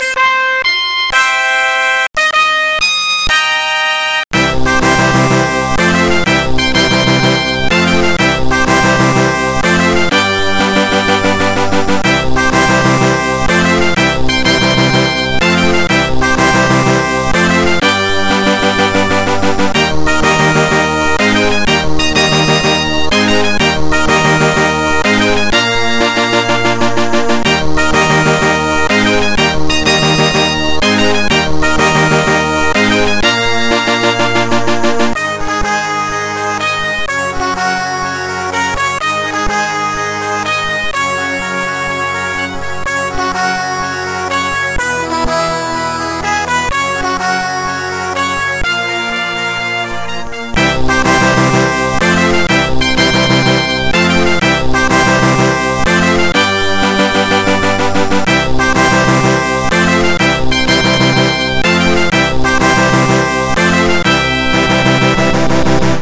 Musica